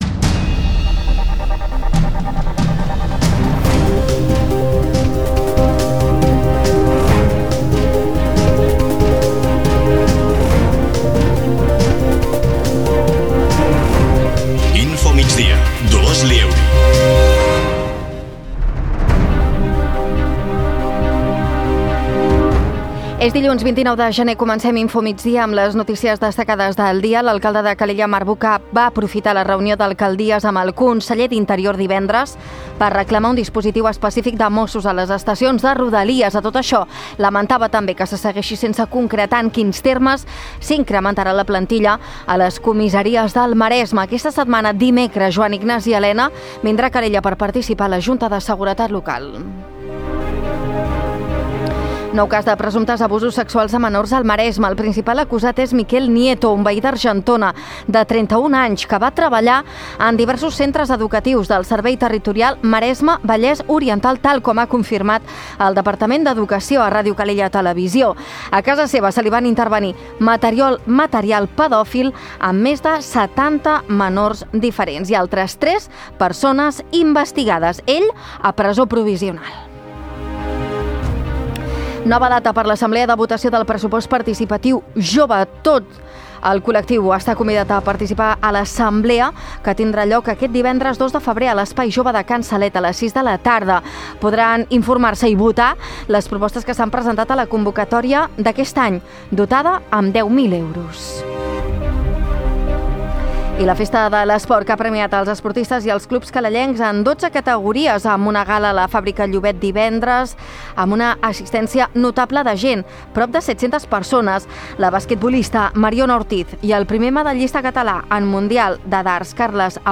Notícies d’actualitat local i comarcal.